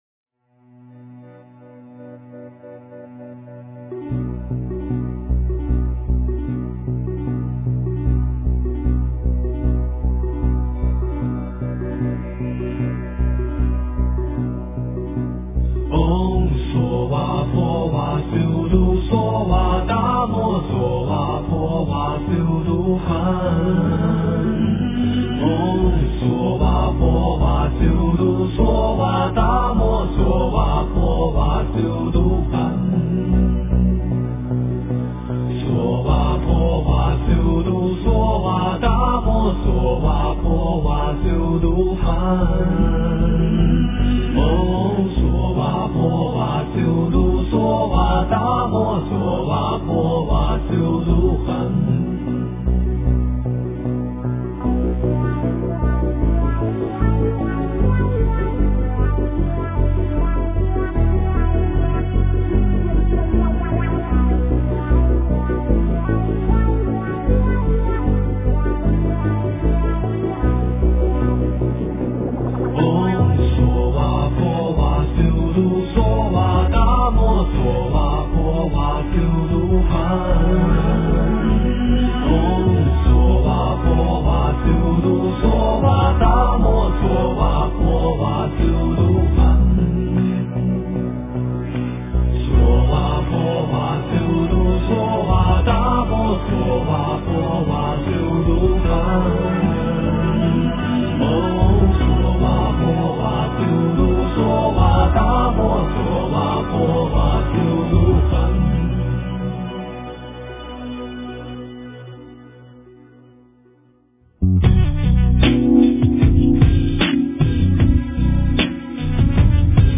诵经
佛音 诵经 佛教音乐 返回列表 上一篇： 心经 下一篇： 大悲咒 相关文章 财宝天王咒--十八一心童声合唱团 财宝天王咒--十八一心童声合唱团...